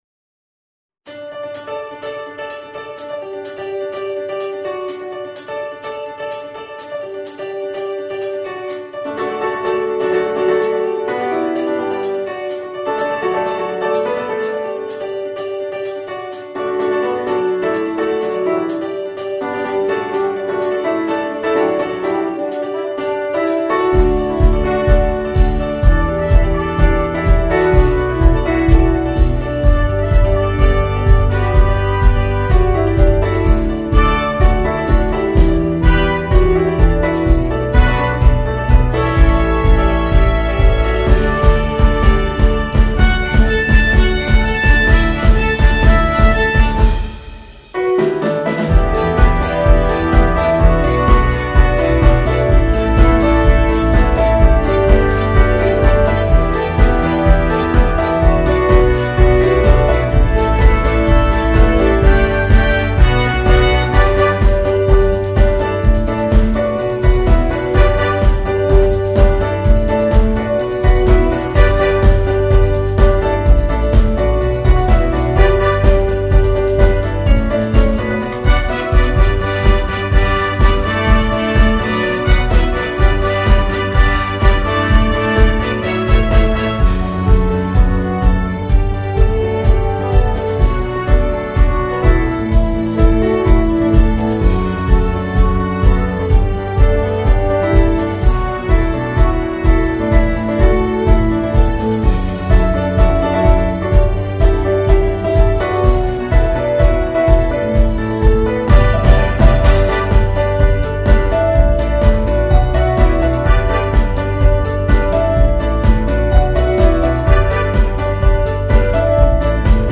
SATB
Closer